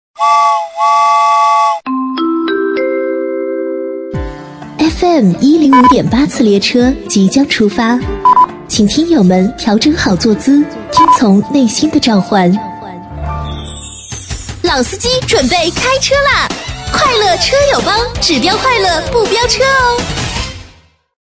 A类女50
【女50号片花】快乐车友帮1
【女50号片花】快乐车友帮1.mp3